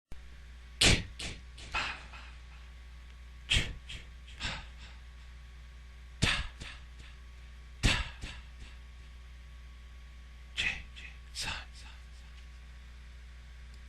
Friday The 13th Horror Sound Effect Free Download